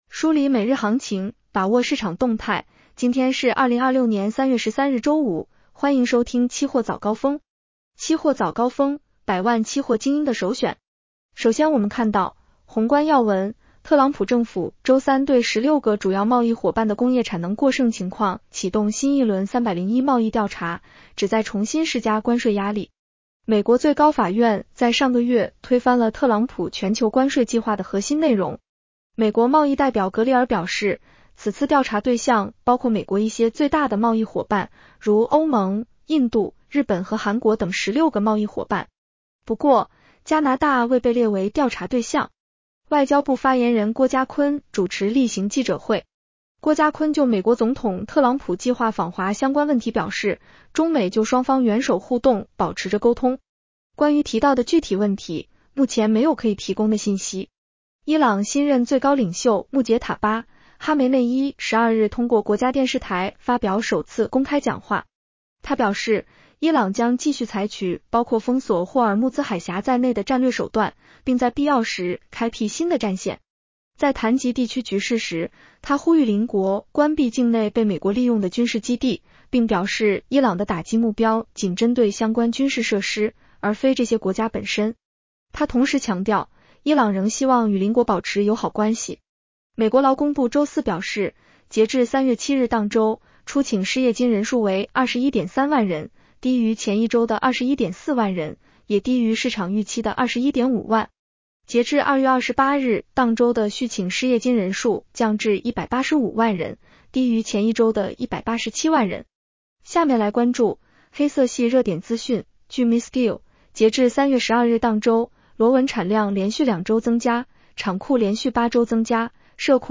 期货早高峰-音频版
期货早高峰-音频版 女声普通话版 下载mp3 热点导读 1.广东省猪粮比价进入过度下跌一级预警区间。